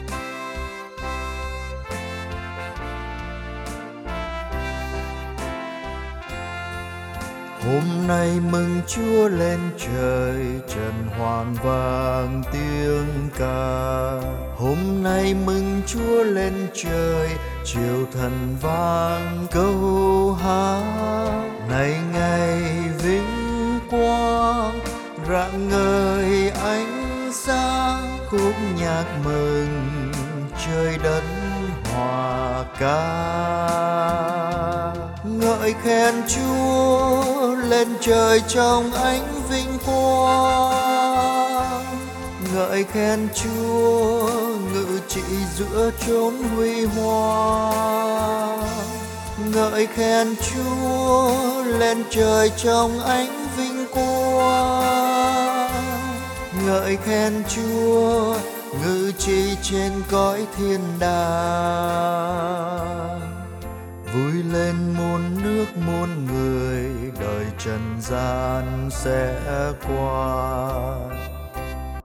HomNayMungChuaLenTroi_Sop.mp3